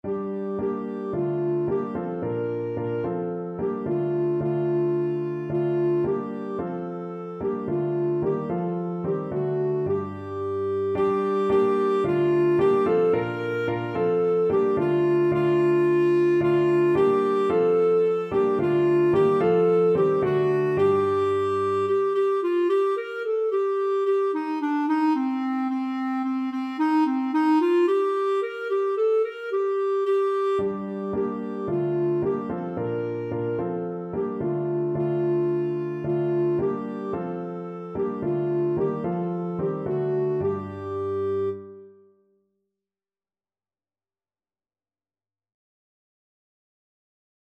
Clarinet
G minor (Sounding Pitch) A minor (Clarinet in Bb) (View more G minor Music for Clarinet )
2/2 (View more 2/2 Music)
Energetic two in a bar = c.110
Classical (View more Classical Clarinet Music)